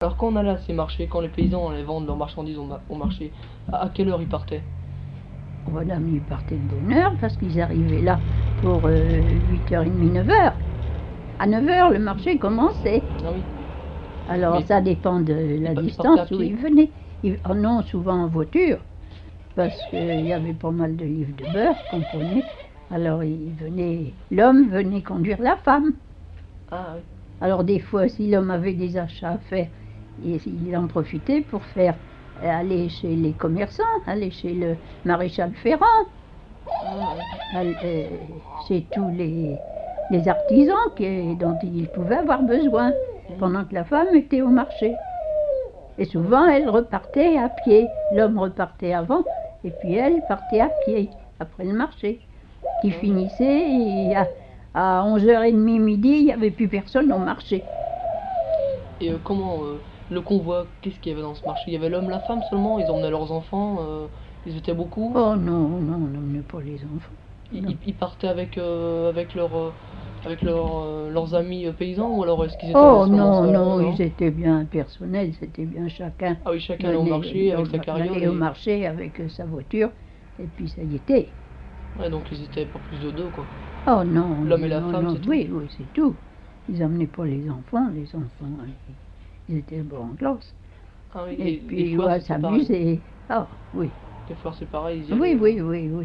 témoignages sur les manifestations de Mortagne-sur-Sèvre : foire, marché, noces, quête de Pâques, Mardi-gras
Témoignage